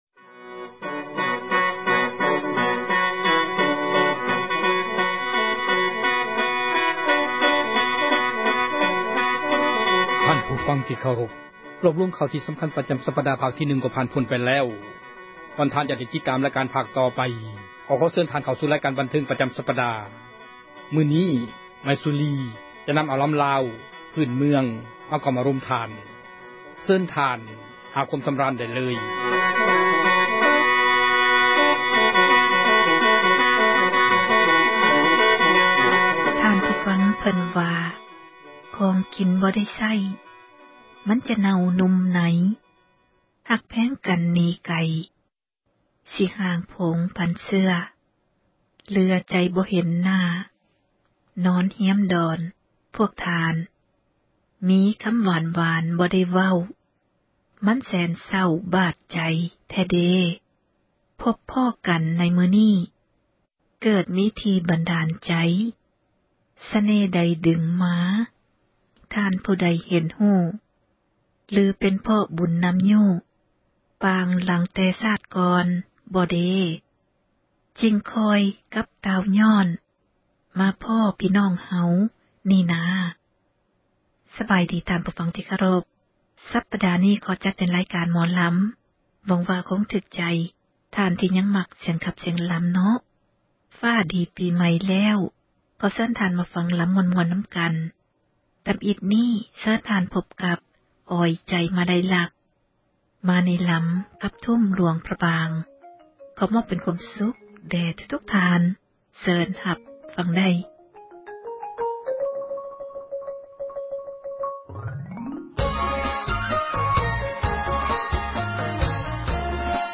ຣາຍການ ໝໍລຳລາວ ປະຈຳ ສັປດາ ຈະນໍາເອົາ ສິລປະ ການຂັບລໍາ ທີ່ເປັນມູນ ມໍຣະດົກ ຂອງລາວ ໃນແຕ່ລະ ຊົນເຜົ່າ ແຕ່ລະ ພາກພື້ນເມືອງ ທີ່ເຮົາຄົນລາວ ຈະຕ້ອງ ສົ່ງເສີມ ແລະ ອະນຸຮັກ ຕໍ່ໄປ.